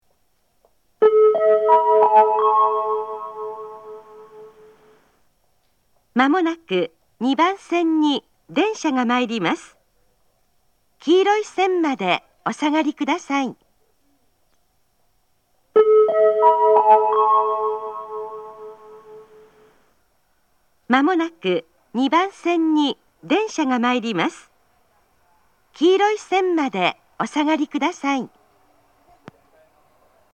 仙石旧型（女性）
接近放送
仙石旧型女性の接近放送です。同じ内容を2度繰り返します。